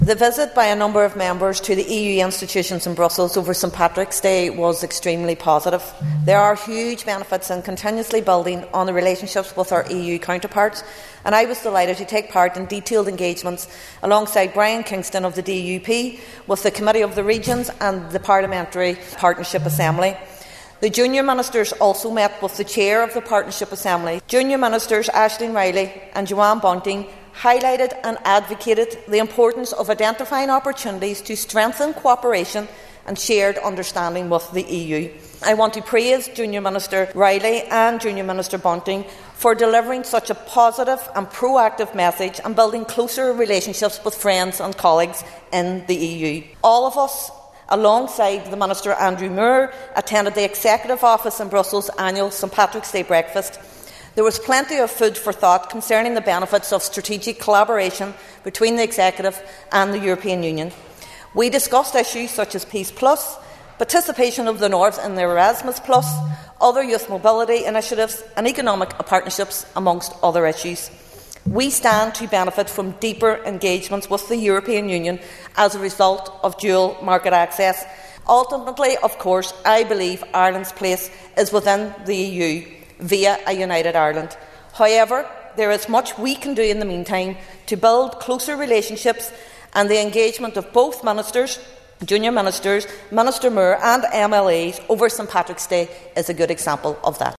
A Sinn Fein member has told the Assembly at Stormont that there’s been very positive engagement between all parties and members of the European Parliament and EU Commission in Brussels.